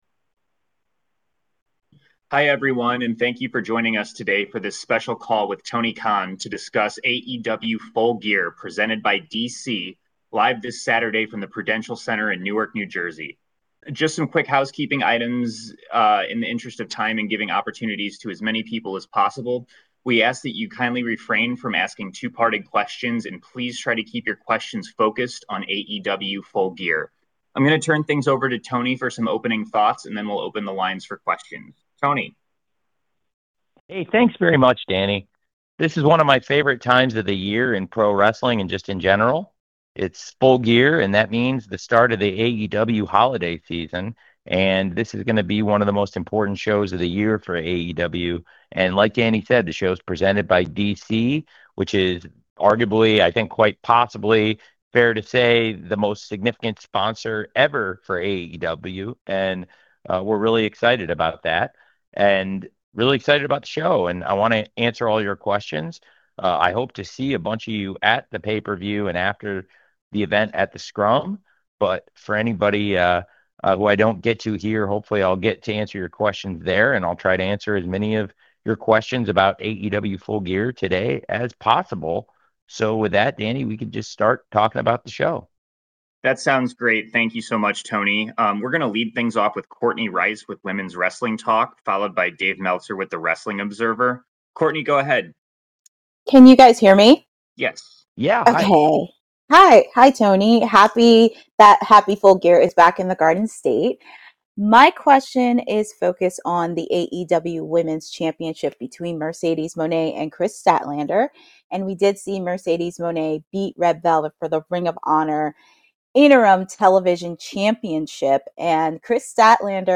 A media call with Tony Khan for AEW Full Gear 2025 discussing the PPV, Okada v Tanahashi, the National Title & Continental Classic.